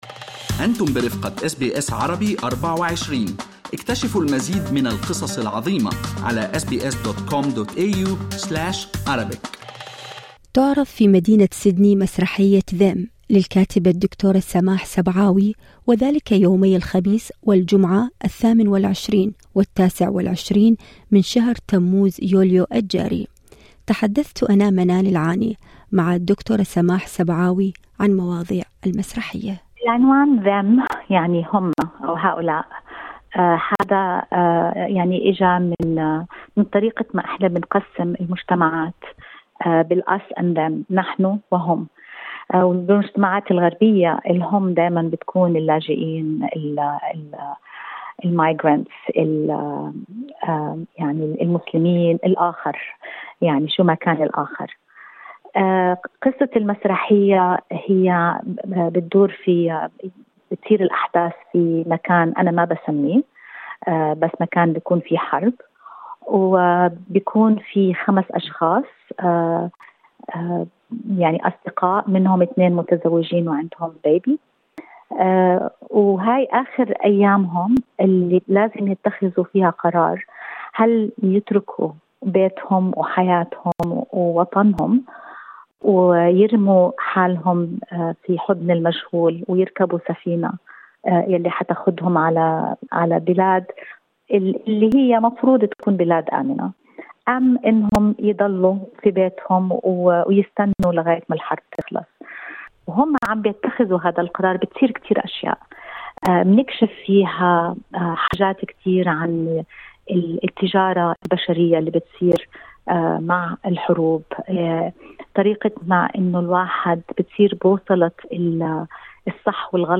في حديث لأس بي أس عربي24